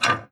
insert_valve.wav